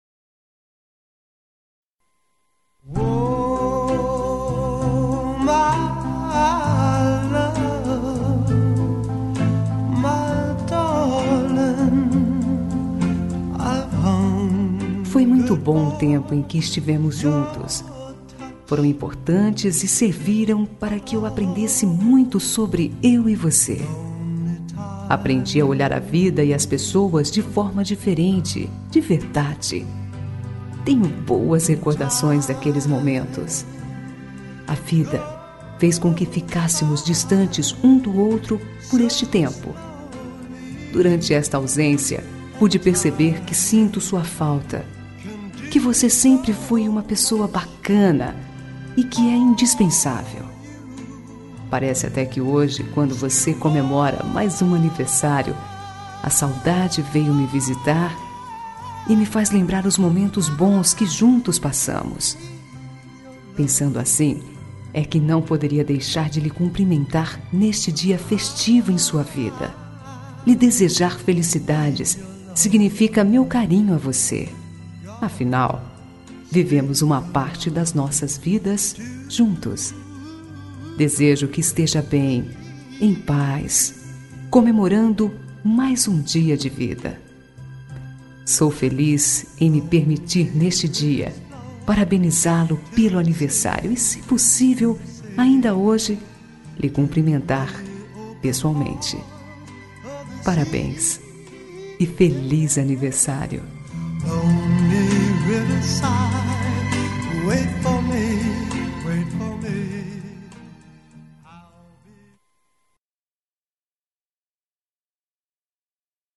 Telemensagem de Aniversário de Ex. – Voz Feminina – Cód: 1369 – Ex. Marido